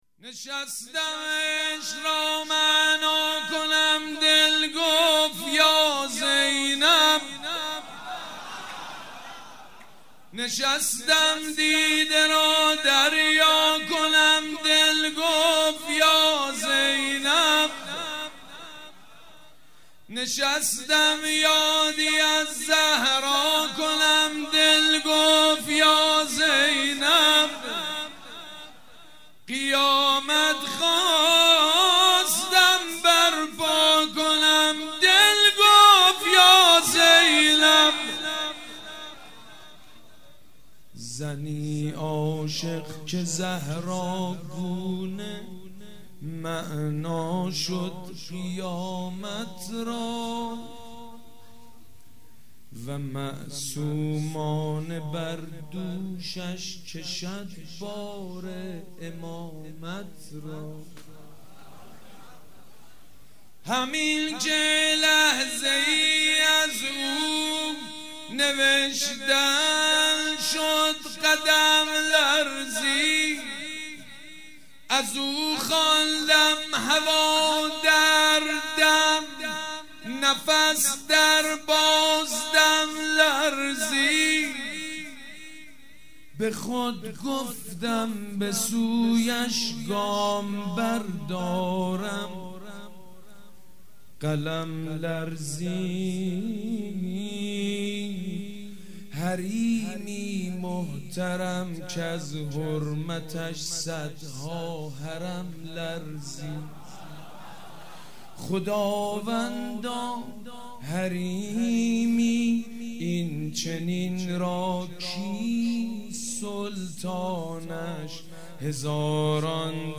خیمه گاه - عاشقان اهل بیت - مدح- نشستم عشق را معنا کنم دل گفت یا زینب- سید مجید بنی فاطمه